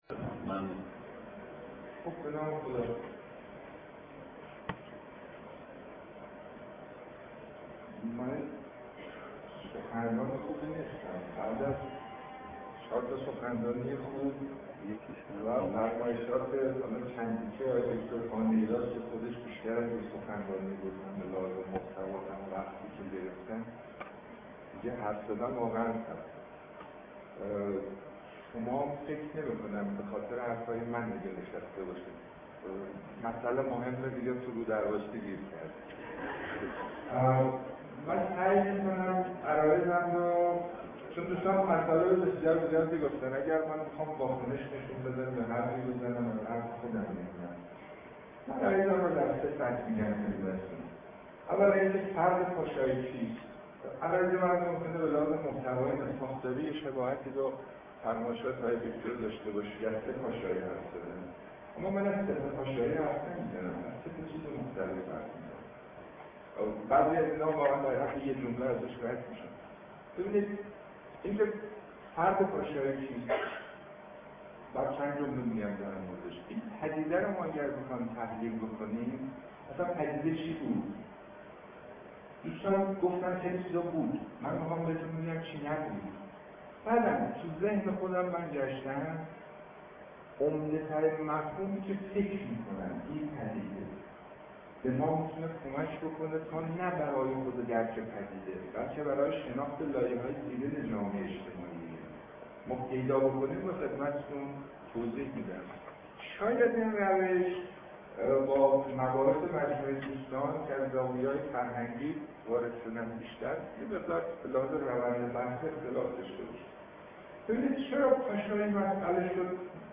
سخنرانی
در نشست پدیده پاشایی است که در دی ماه ۹۳ به همت انجمن جامعه‌شناسی ایران در دانشکده علوم اجتماعی دانشگاه تهران برگزار شد.